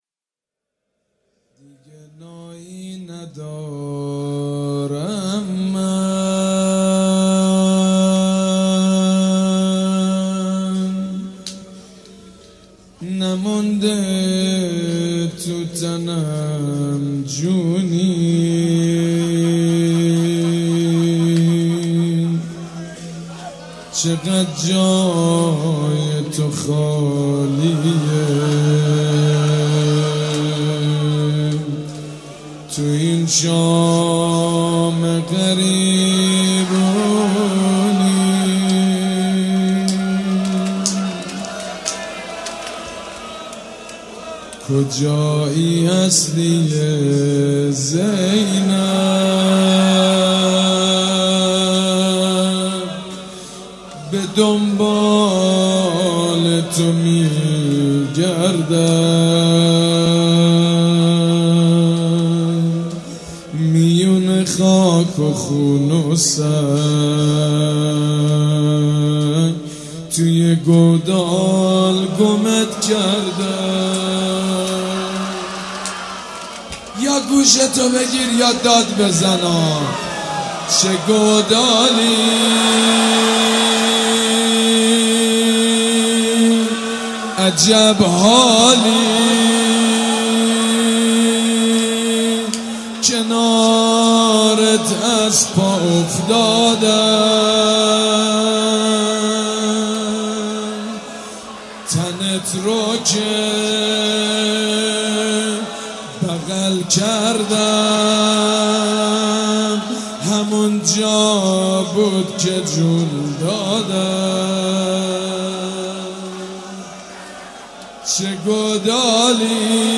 شب یازدهم محرم 95_ روضه_دیگه نایی ندارم من